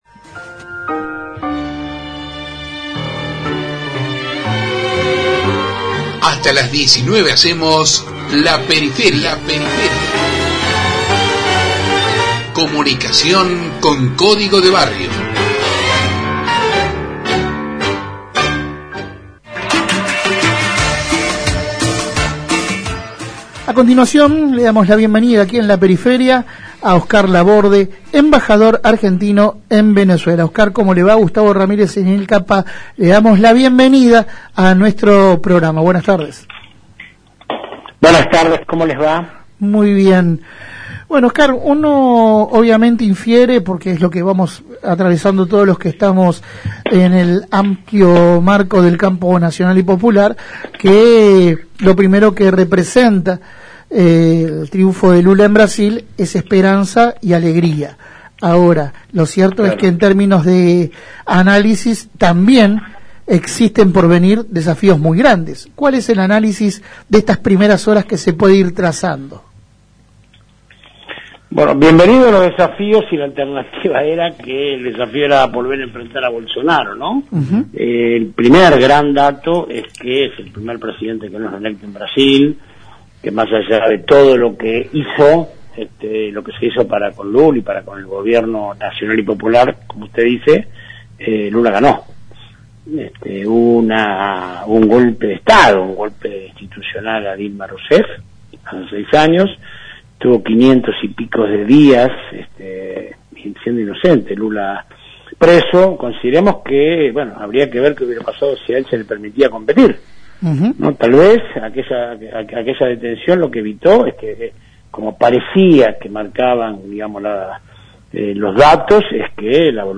Compartimos la entrevista completa: